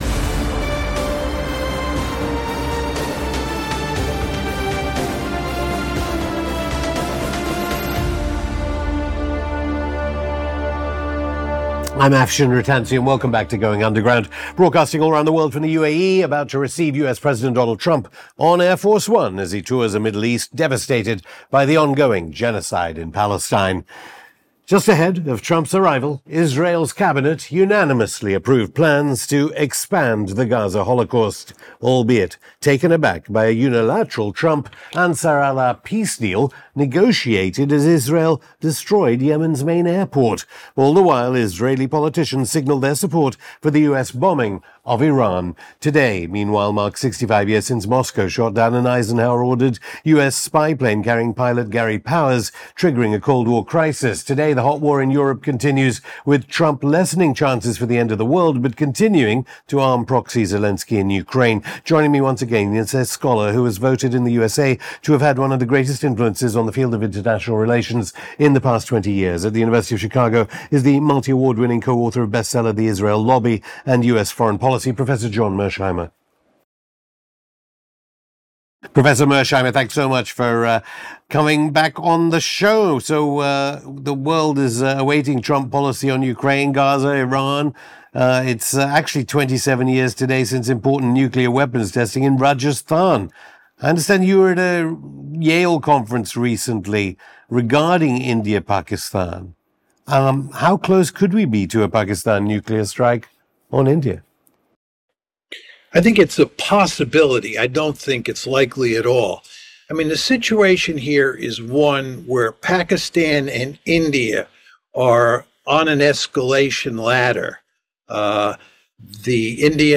In the interview